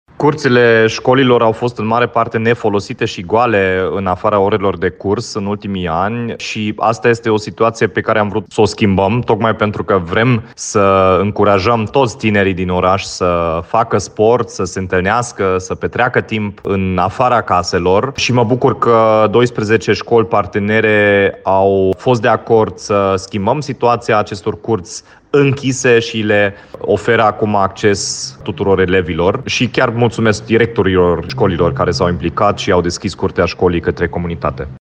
Terenurile de sport ale școlilor oricum nu erau folosite decât în timpul orelor de curs, spune primarul Dominic Fritz.